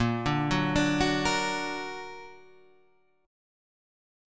A# Augmented